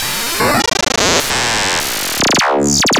Glitch FX 27.wav